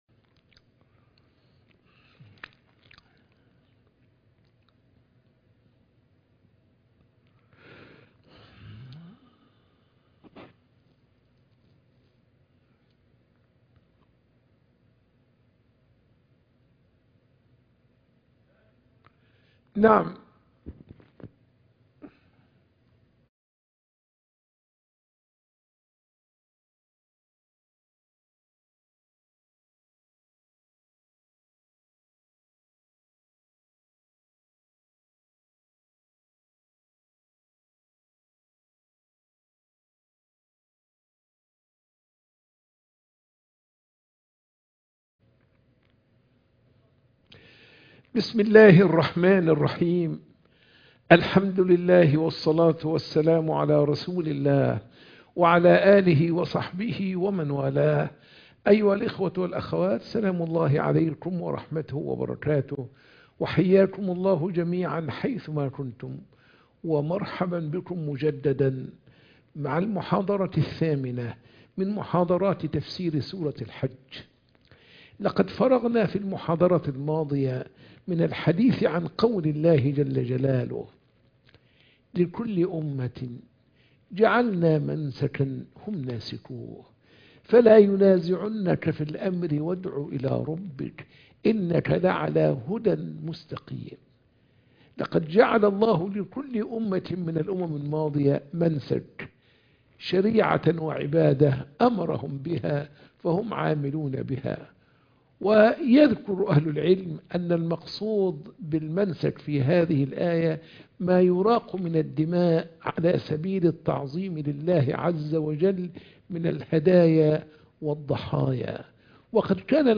محاضرة التفسير